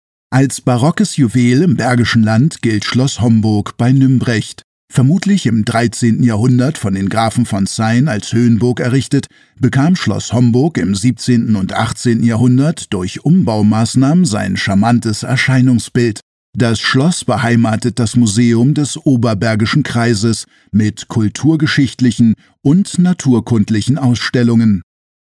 audioguide-schloss-homburg.mp3